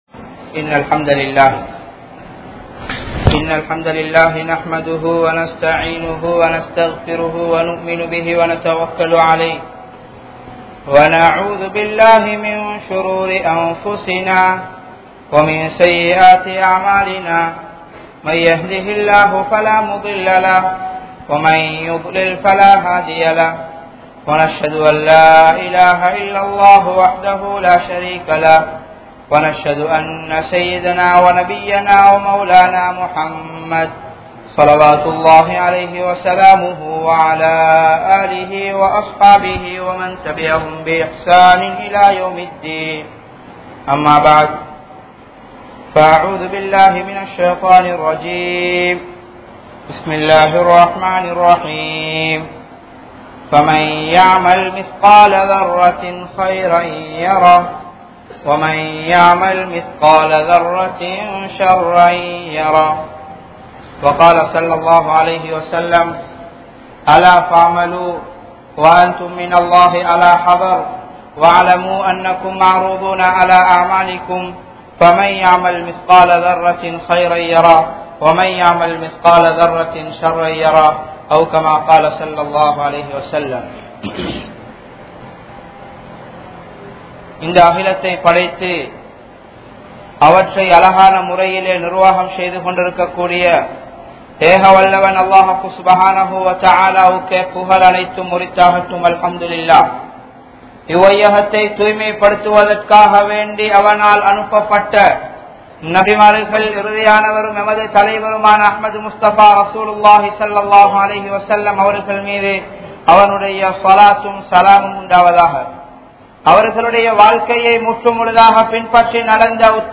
Allah`vukku Nantri Seluthugal(அல்லாஹ்வுக்கு நன்றி செலுத்துங்கள்) | Audio Bayans | All Ceylon Muslim Youth Community | Addalaichenai